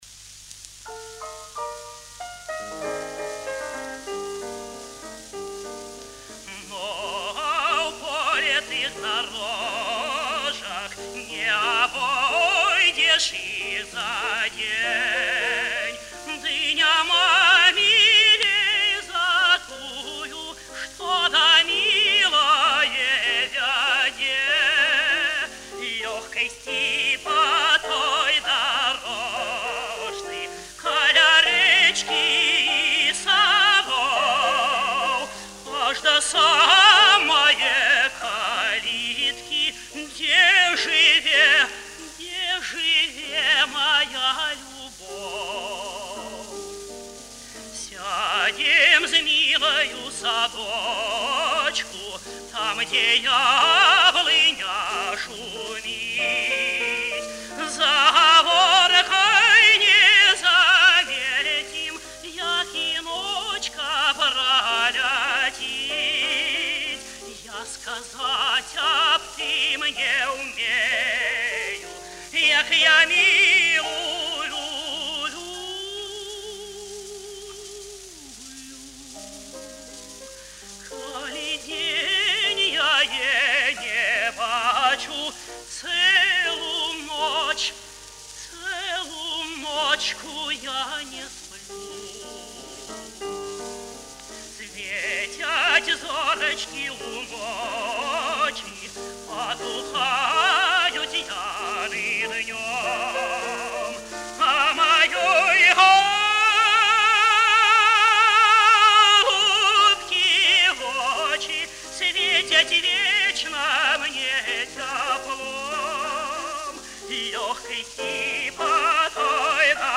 A half-hour documentary on him on Belarussian TV used a few recordings with piano accompaniment (two songs and Pourquoi me réveiller), all very well-done with a slender, lyrical, excellently focused voice.
and a Soviet song of a more popular kind, in Belarussian